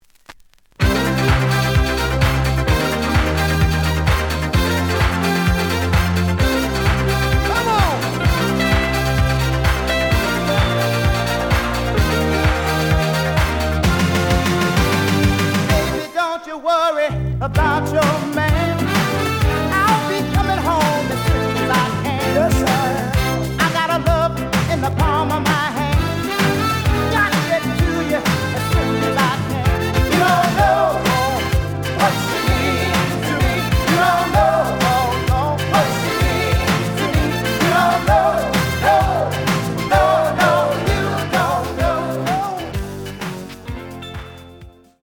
The audio sample is recorded from the actual item.
●Genre: Soul, 80's / 90's Soul
Slight edge warp. But doesn't affect playing. Plays good.)